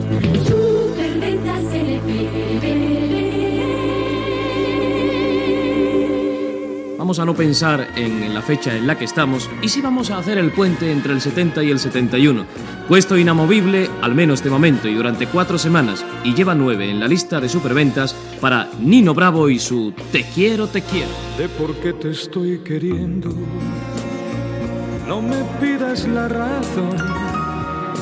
Indicatiu del programa, prresentació d'una cançó de Nino Bravo.
Musical